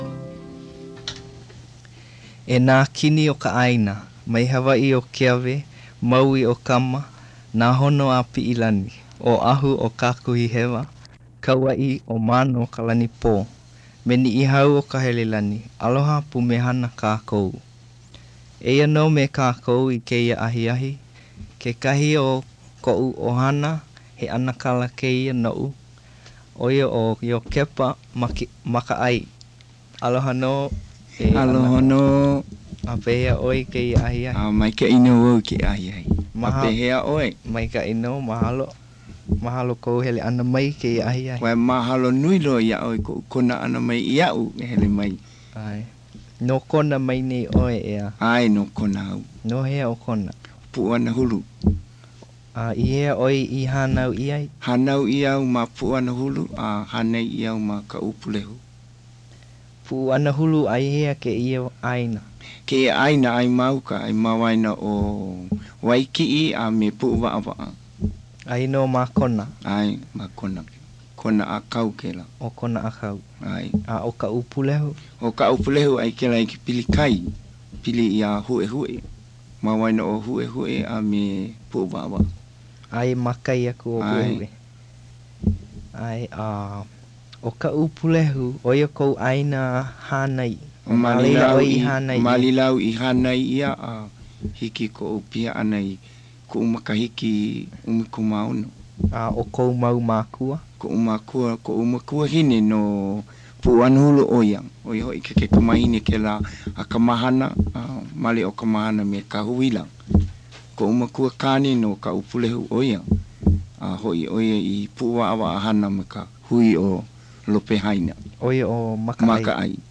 Interviewer
digital wav file (44.1 kHz/16 bit); orginally recorded at KCCN studios onto reel-to-reel tape, then digitized to mp3, then converted to wav
Hawaiʻi; recordings made in Honolulu, Hawaiʻi